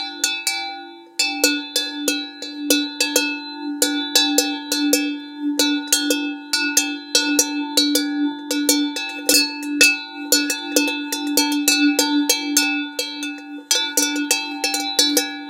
Kravský zvon s kvetinou 26 / 12cm
zvon-na-kravu.m4a